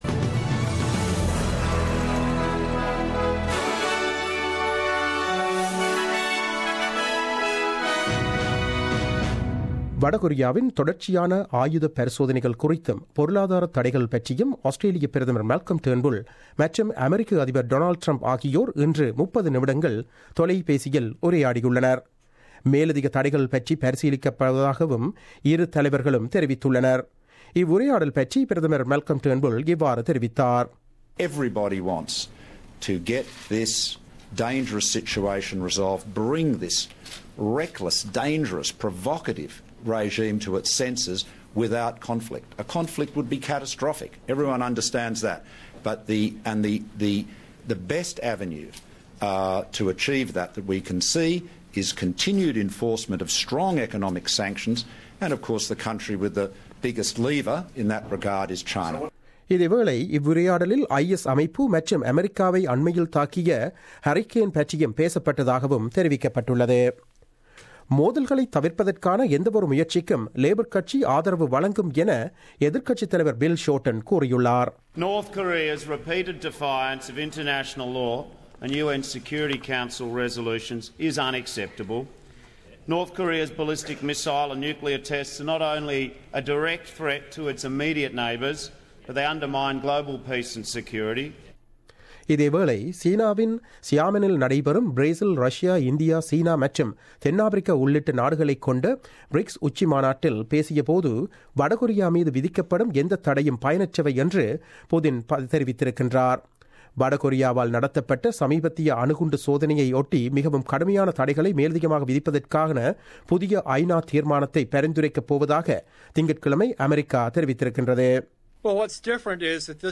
The news bulletin broadcasted on 06 September 2017 at 8pm.